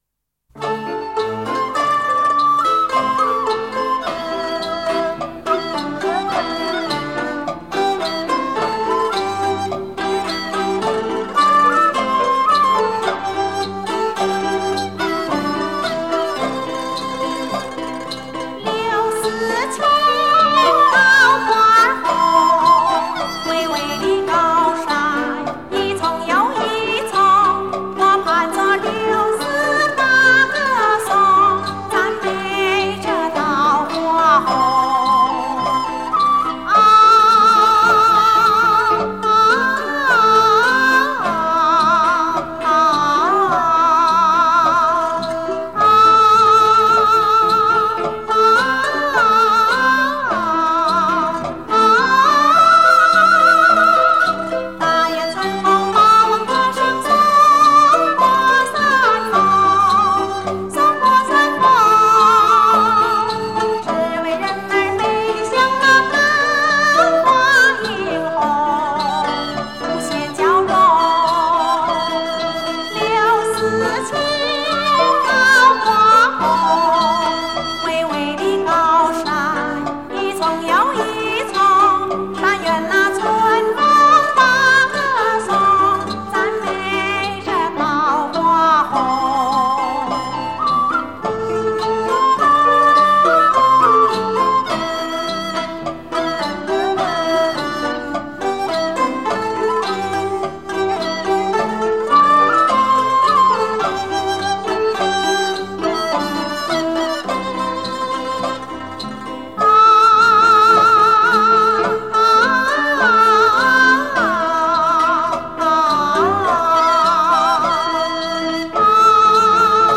那些小調歌曲唱片，有她當年代的特別風格，